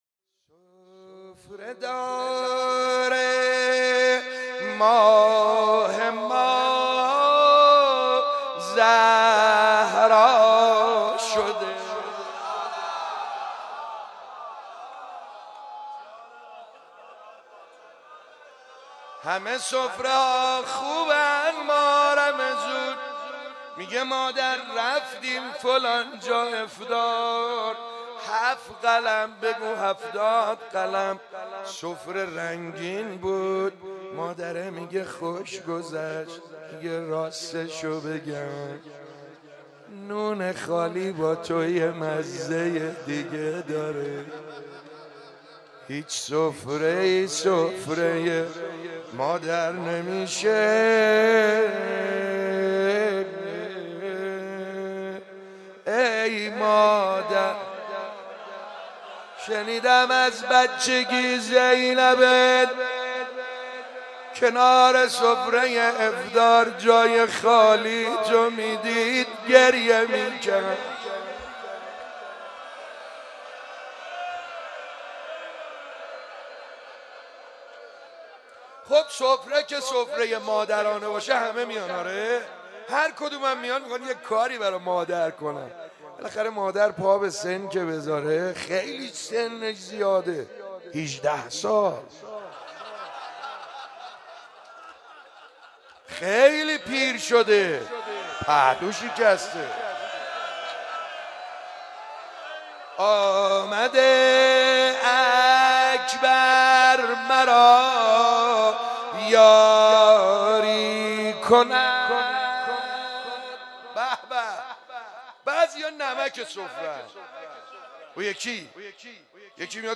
در مهدیه امام حسن مجتبی(ع) برگزار گردید.
روضه روضه دعا و مناجات دعا و مناجات سینه زنی لینک کپی شد گزارش خطا پسندها 0 اشتراک گذاری فیسبوک سروش واتس‌اپ لینکدین توییتر تلگرام اشتراک گذاری فیسبوک سروش واتس‌اپ لینکدین توییتر تلگرام